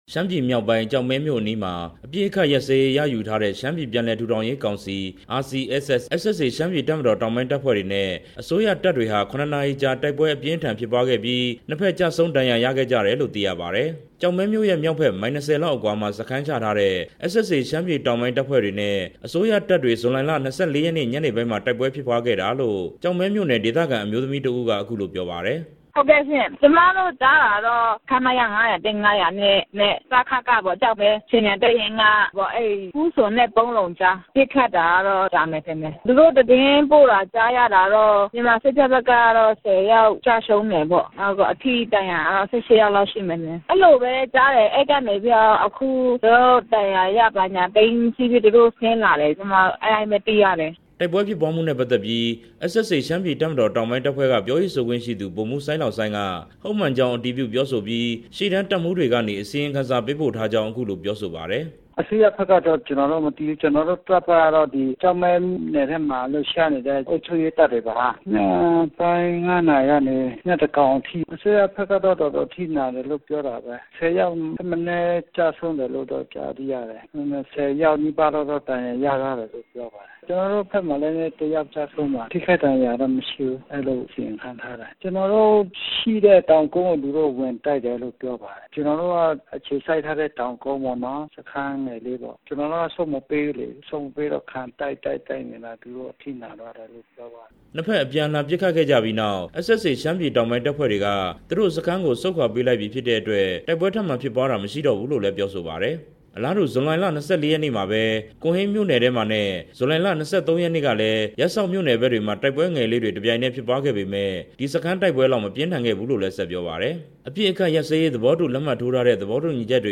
တိုက်ပွဲအကြောင်း တင်ပြချက်